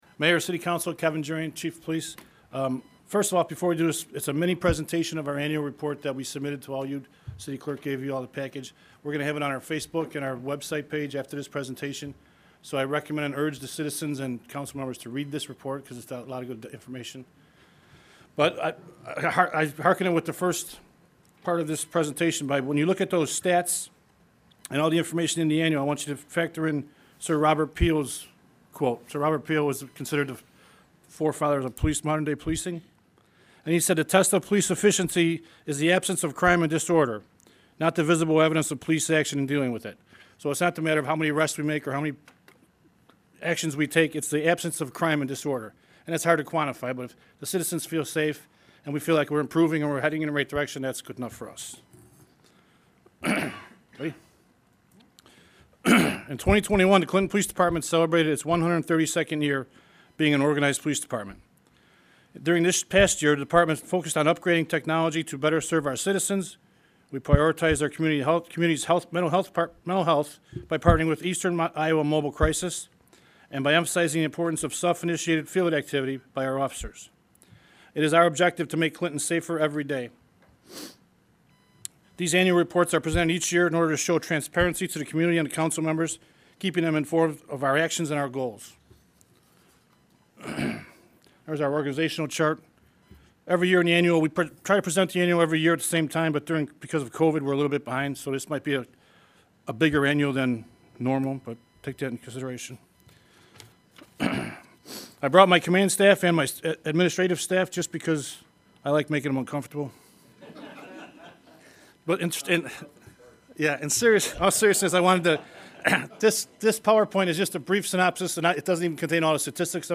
Listen to the report from Clinton Police Kevin Gyrion to the Clinton City Council and see link below to the report